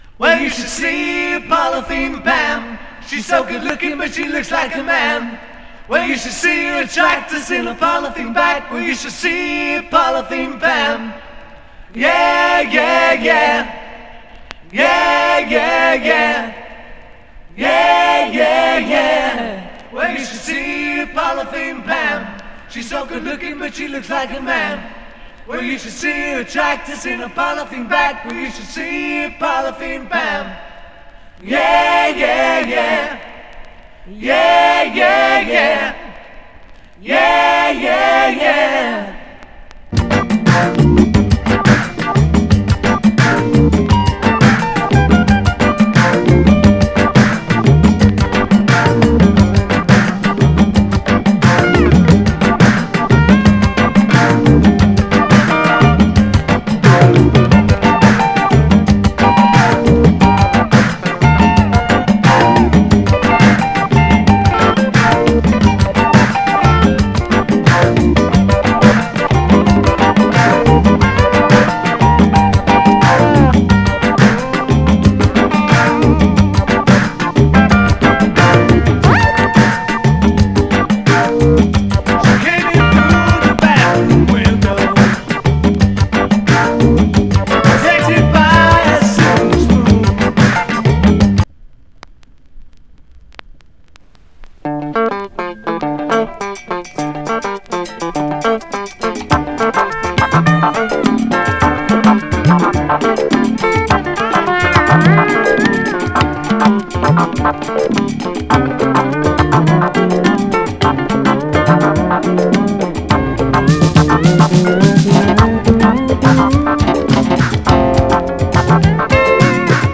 Genre:            Pop, Folk, World, & Country
Style:              African